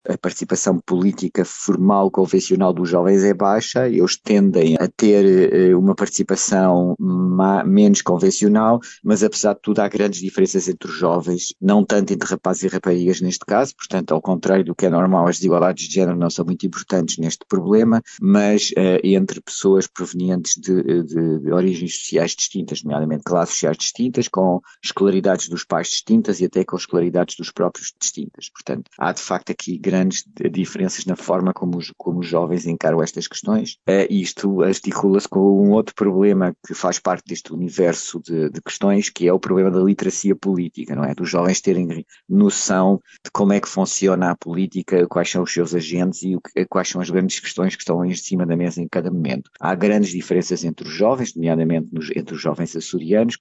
Em entrevista à Atlântida